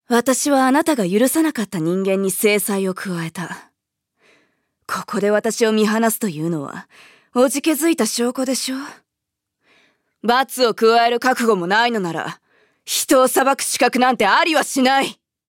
음성 대사